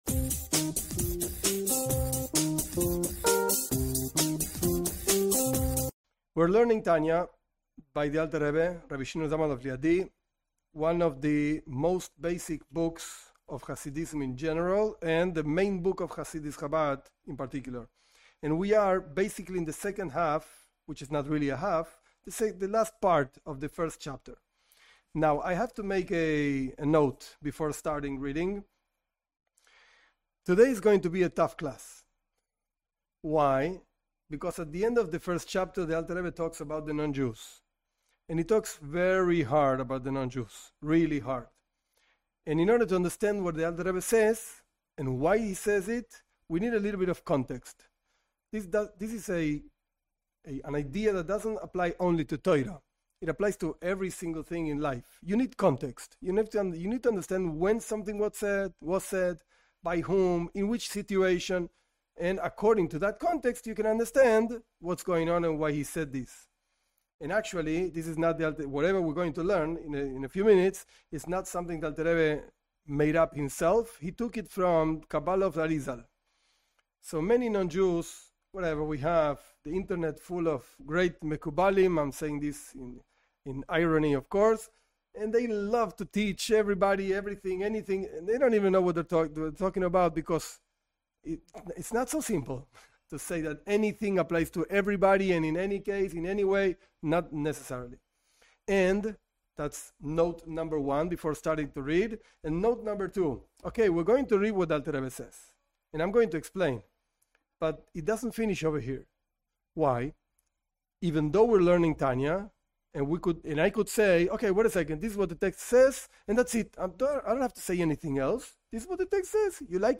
This class teaches Tanya, Book of Beinonim (intermediate people). This is the most basic book of Chabad Chassidism by the Alter Rebbe, Rabi Shneur Zalman of Liadi, of blessed memory.